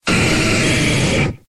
Cri de Nidoking dans Pokémon X et Y.